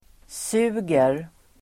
Uttal: [s'u:ger]